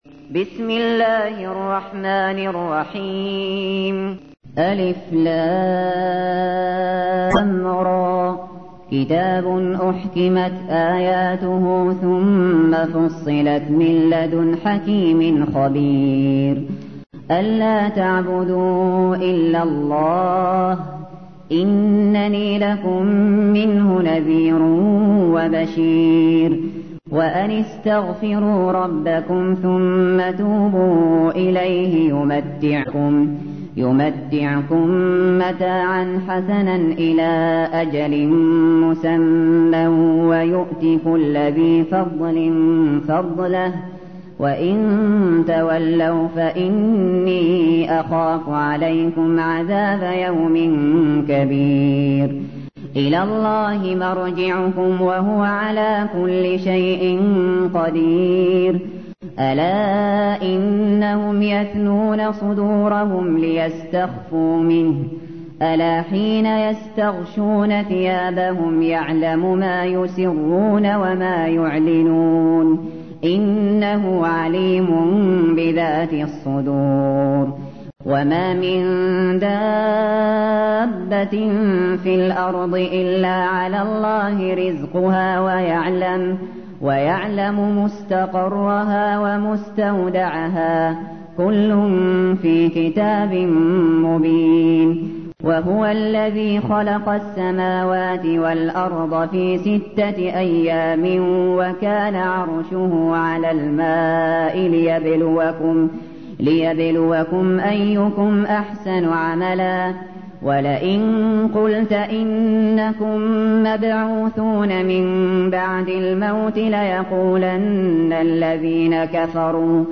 تحميل : 11. سورة هود / القارئ الشاطري / القرآن الكريم / موقع يا حسين